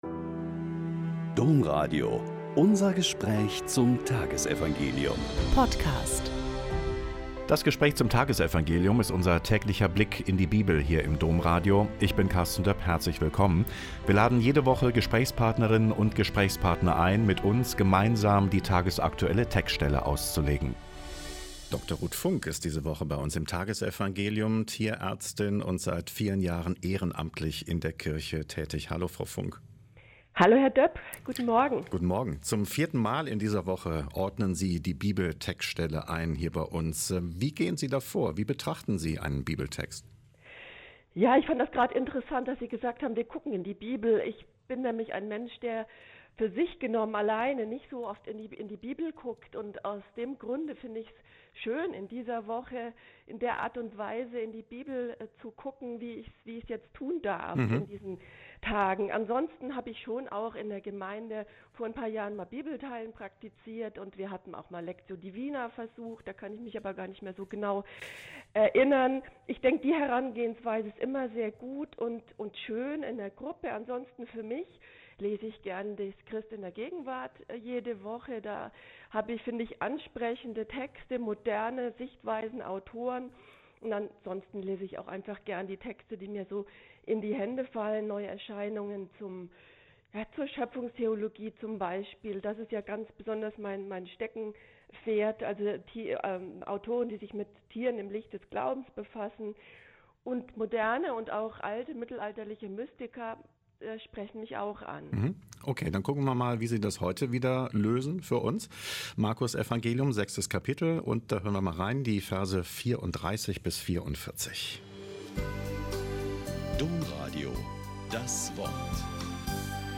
Mk 6,34-44 - Gespräch